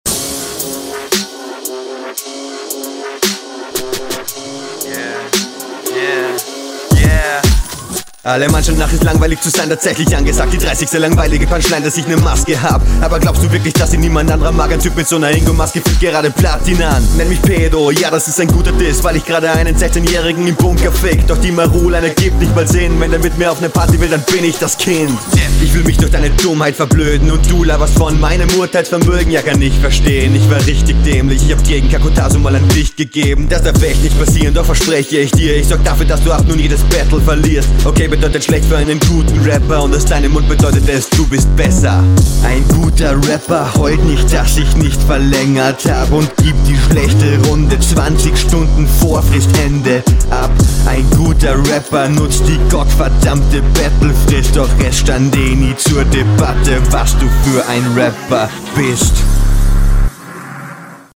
deine Stimme kommt nicht ganz so nice wie die von deinem Gegner auf dem Beat. …
flowlich auch etwas zu schnell für den beat an manchen stellen, aber weniger störend als …